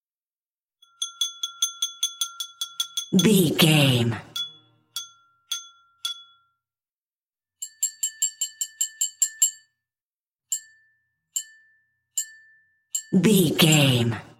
Glass clink fork
Sound Effects
foley